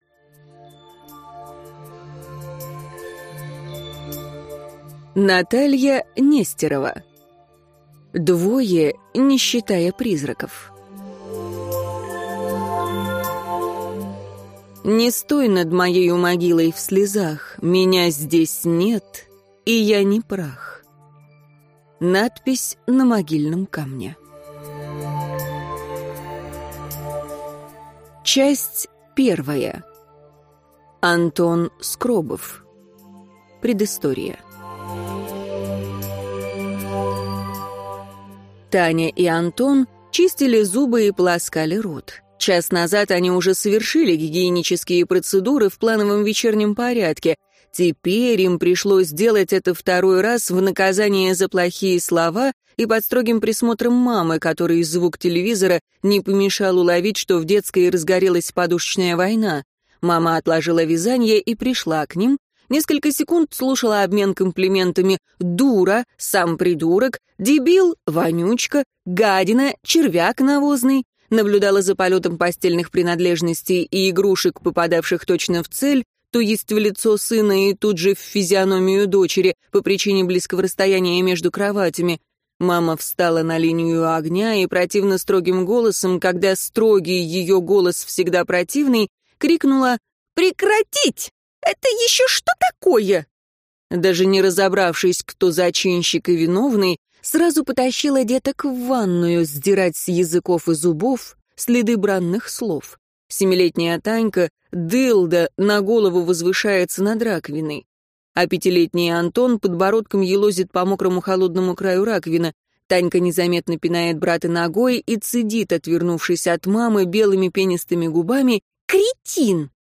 Аудиокнига Двое, не считая призраков | Библиотека аудиокниг
Прослушать и бесплатно скачать фрагмент аудиокниги